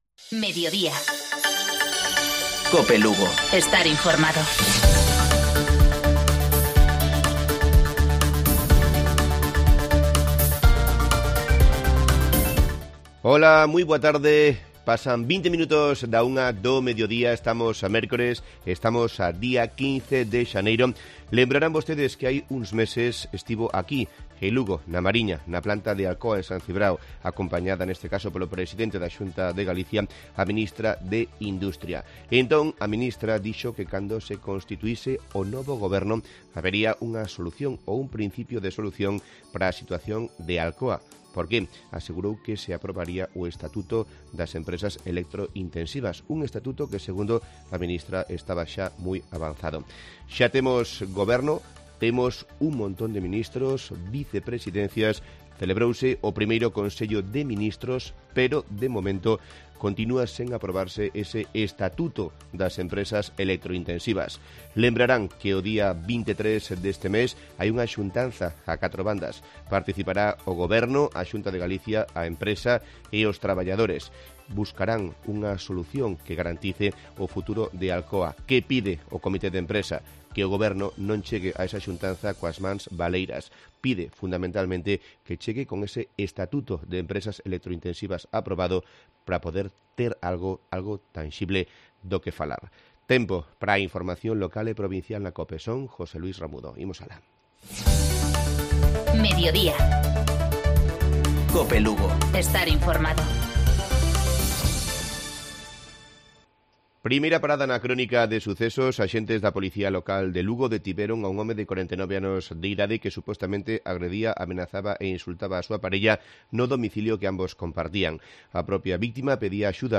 Informativo Mediodía Cope Lugo. Miércoles, 15 de enero de 2020. 13:20-13:30 horas